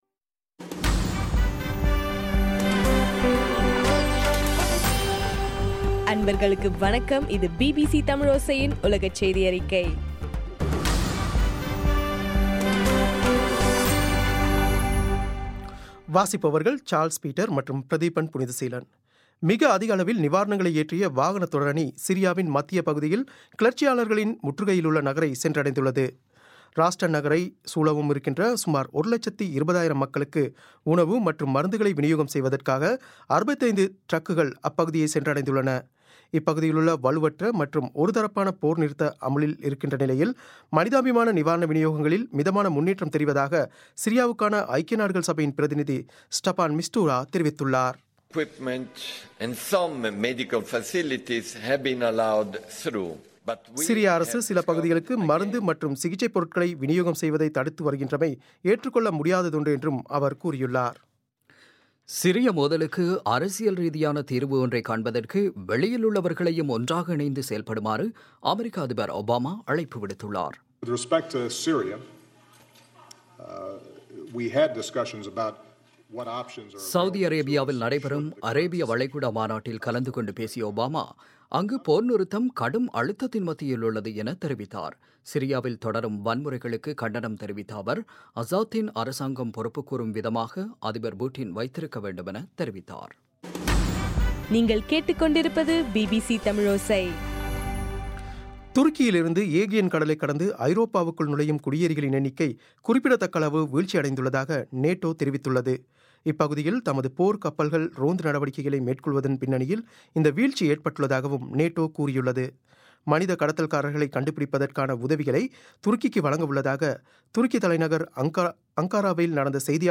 பிபிசி தமிழோசை- உலகச் செய்தியறிக்கை- ஏப்ரல் 21